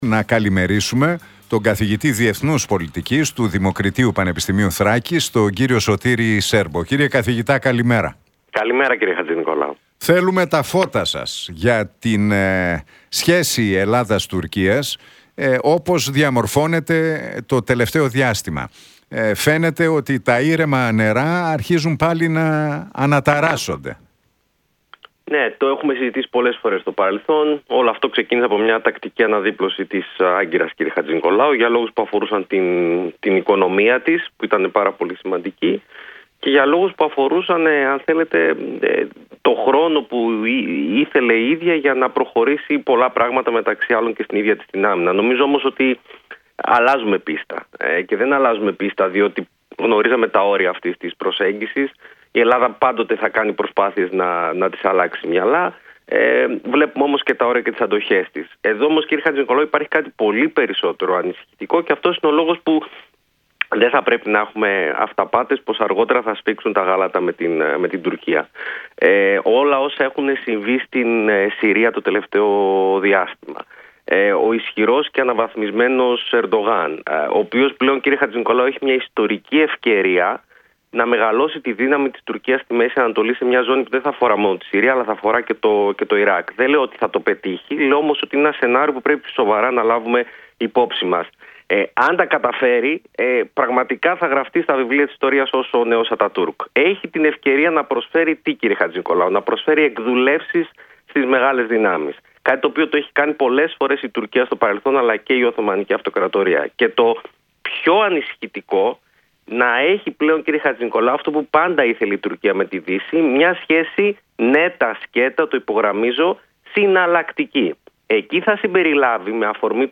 από την συχνότητα του Realfm 97,8.